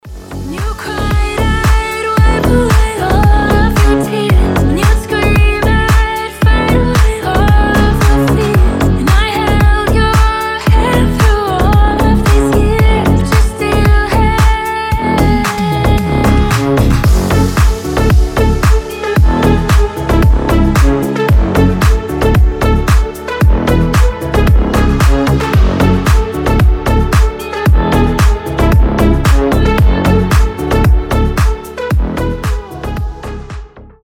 • Качество: 320, Stereo
remix
deep house
retromix
Club House
Cover Mix